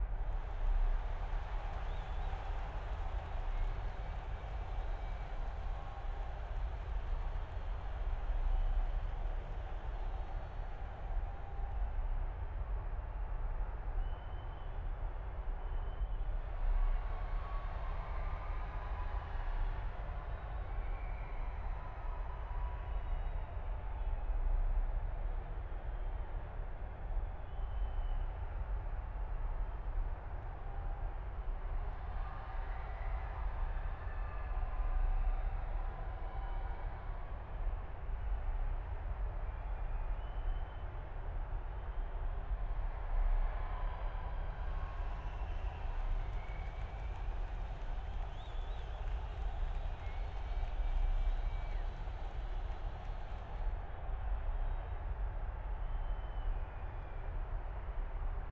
sfx-tft-set10-amb-main.ogg